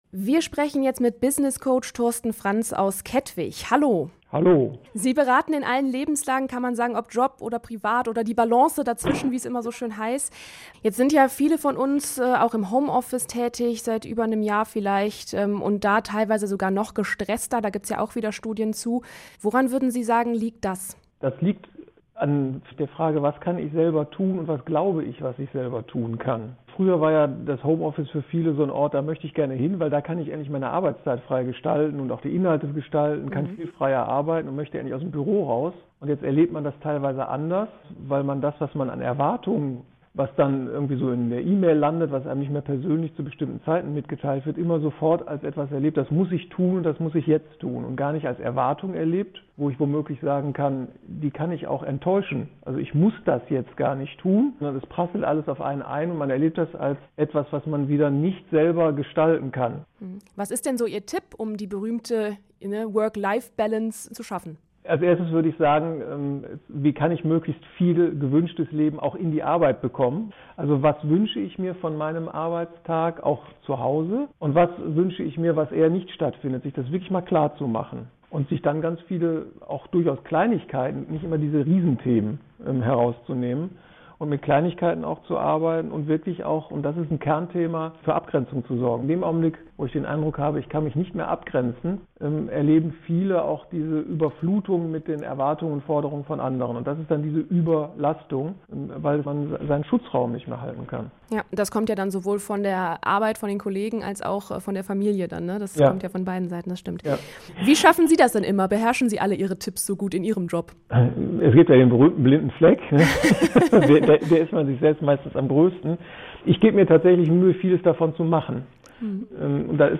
Wir haben dazu mit einem Essener Coach gesprochen.
int-work-life-balance.mp3